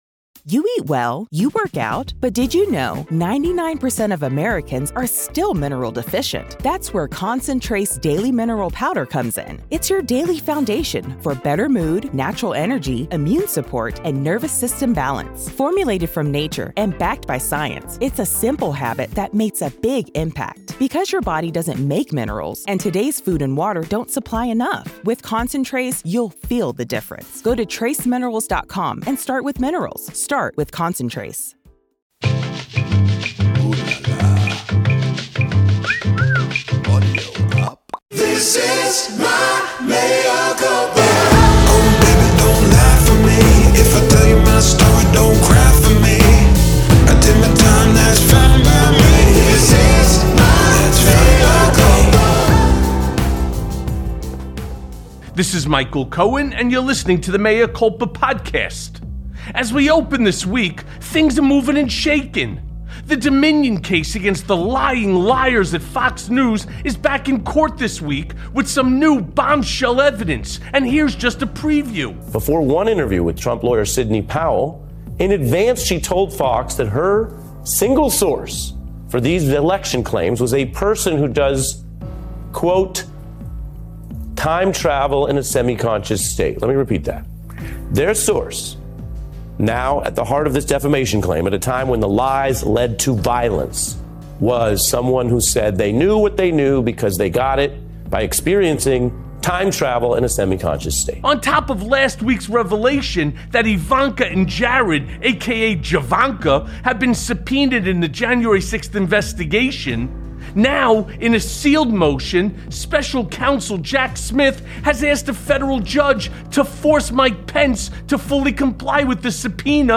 Mea Culpa welcomes friend and fellow lawyer, Katie Phang.
Michael and Katie dig into all of Trump's legal woes.